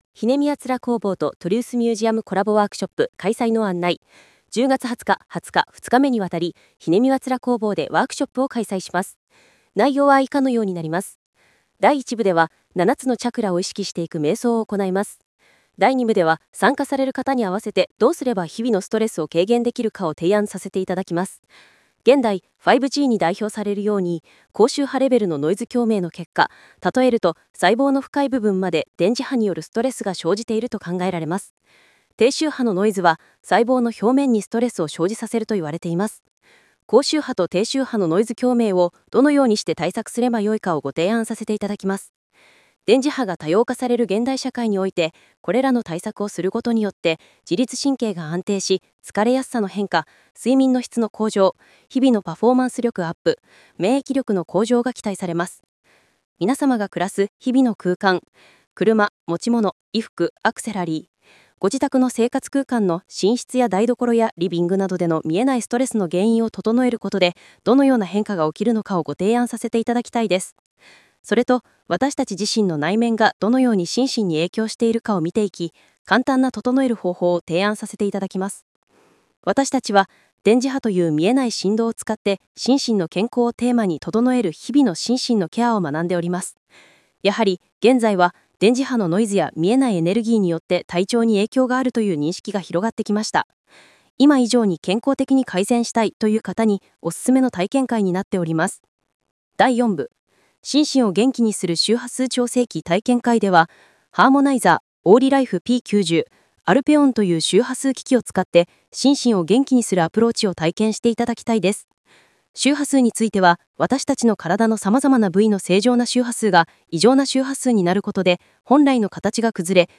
音声ガイド